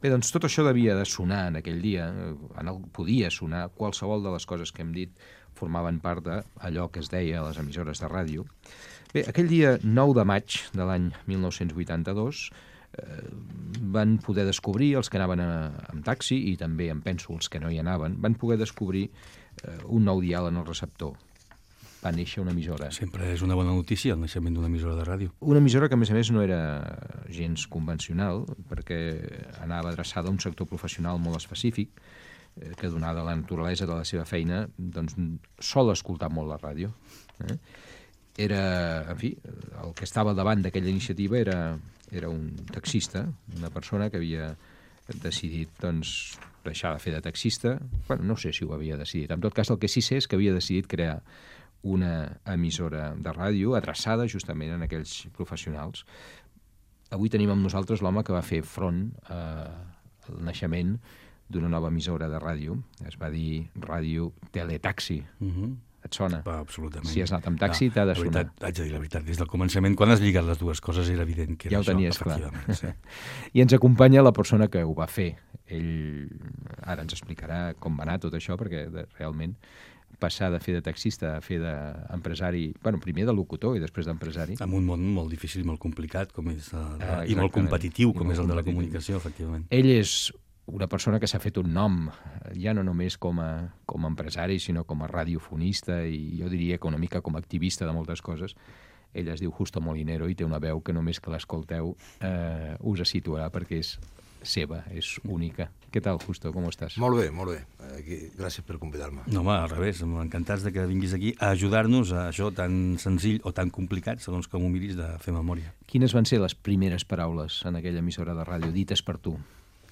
Fragment d'una entrevista a Justo Molinero. Recorden el 9 de maig de 1982, data d'inauguració de Radio Tele-Taxi.
Fragment extret de l'arxiu sonor de COM Ràdio.